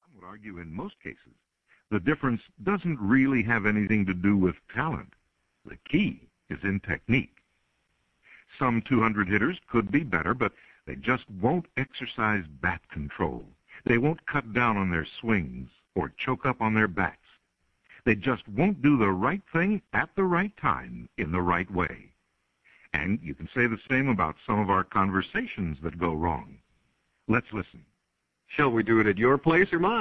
Audio Book (MP3 File)